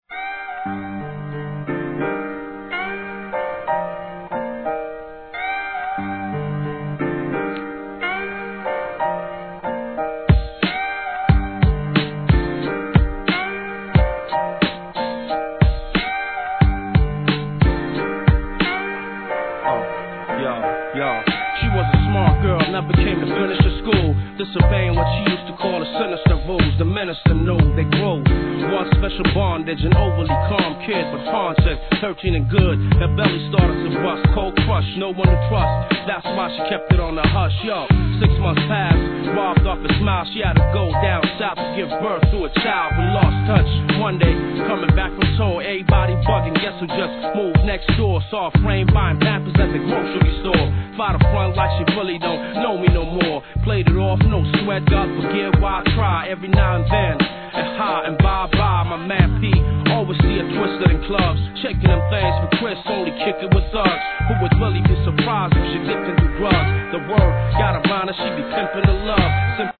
HIP HOP/R&B
メロウでメランコリックなJazzネタ選びとサンプリングコラージュのセンスをご堪能下さい!!!